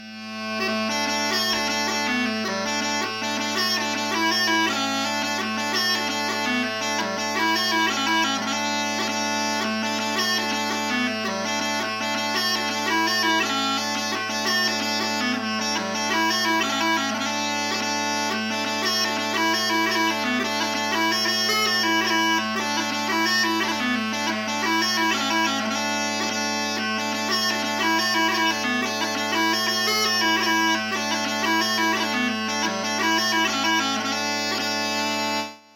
The tune hopefully gives an auditory depiction of this.
Jig